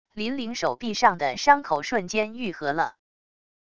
林灵手臂上的伤口瞬间愈合了wav音频生成系统WAV Audio Player